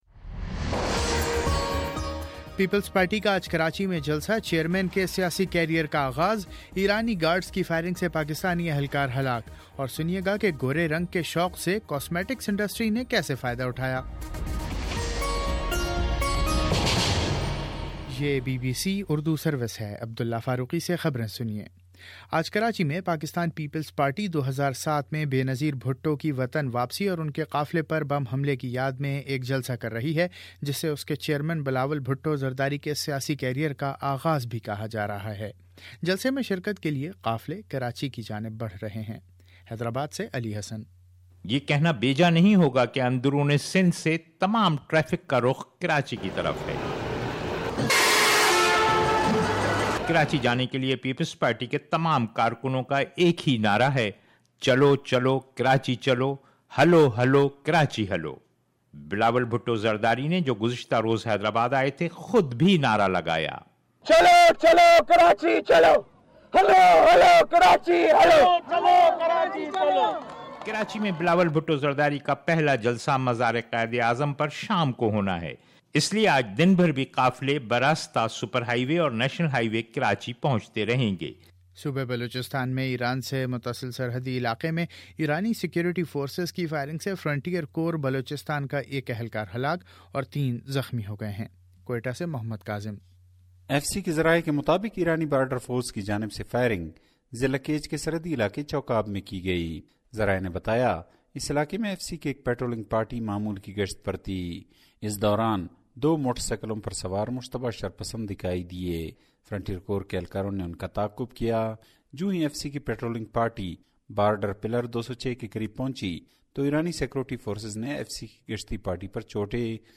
اکتوبر18 : صبح نو بجے کا نیوز بُلیٹن